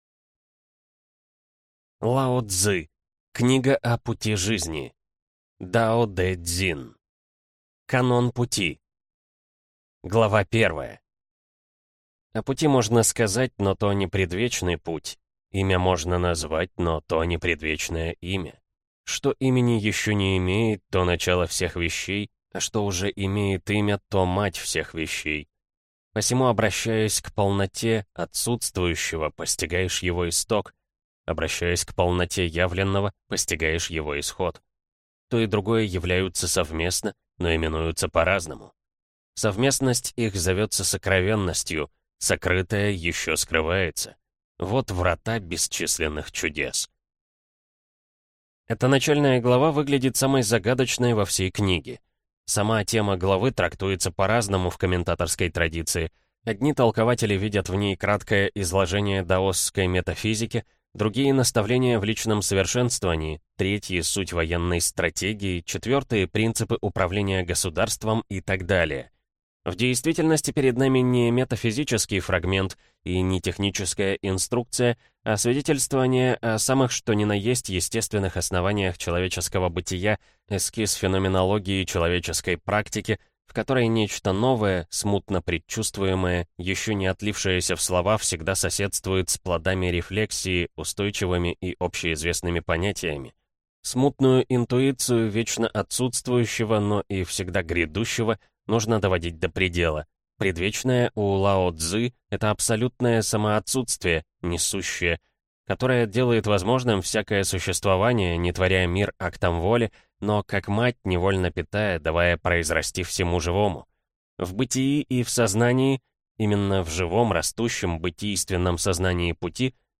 Аудиокнига Книга о Пути жизни | Библиотека аудиокниг